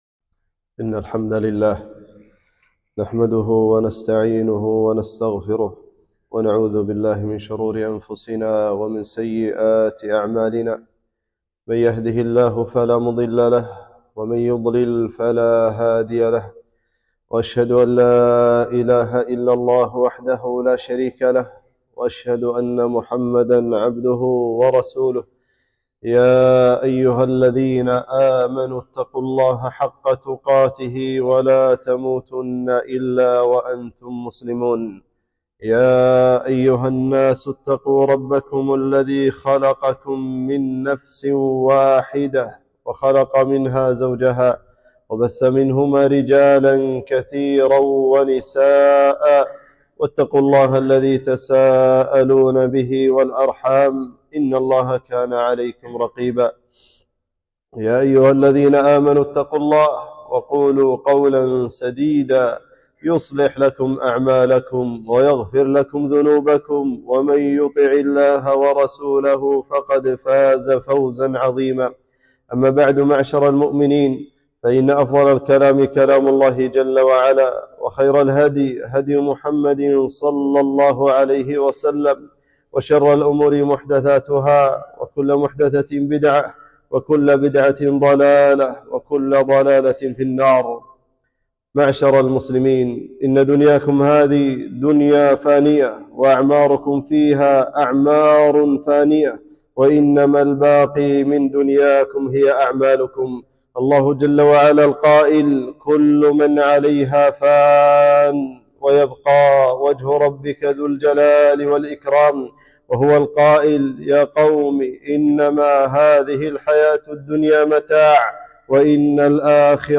خطبة - كفى بالموت واعظا